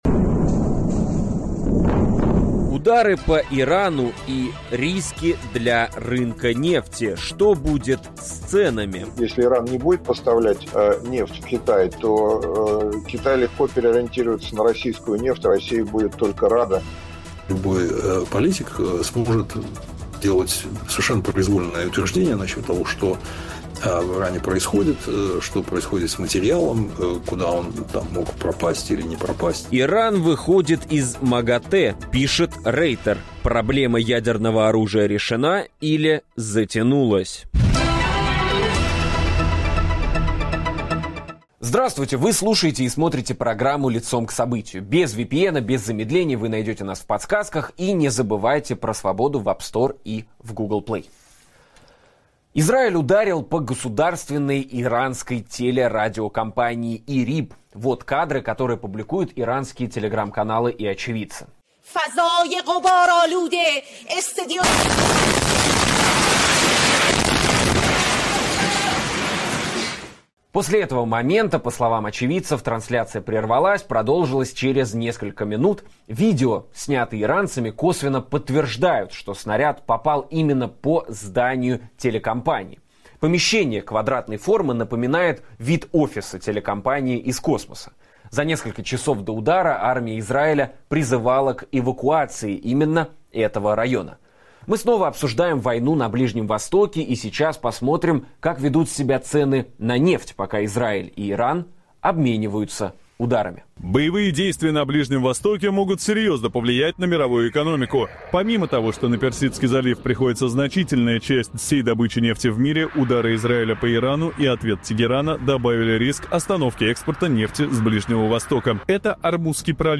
Как конфликт на Ближнем Востоке отражается на ценах на нефть и мировой экономике, кто заинтересован в затягивании конфликта, а кто действительно может выступить посредником – говорим с политологом